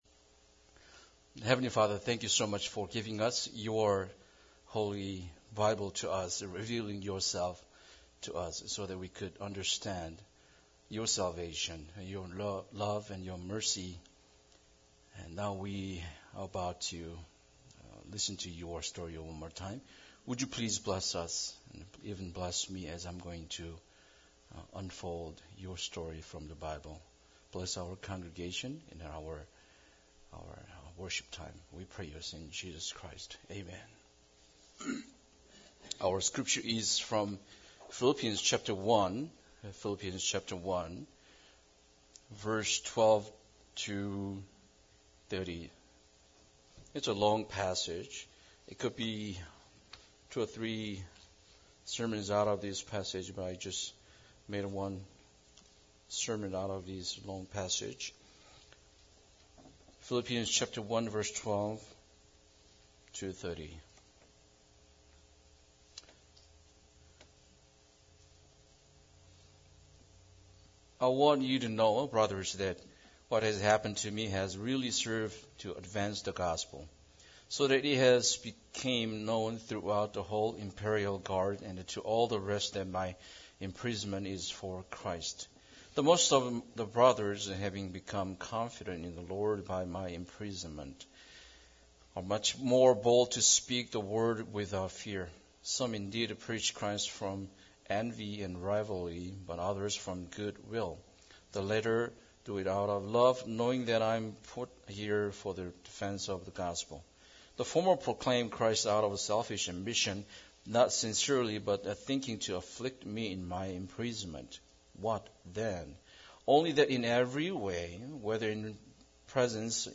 Philippians 1:12-30 Service Type: Sunday Service Bible Text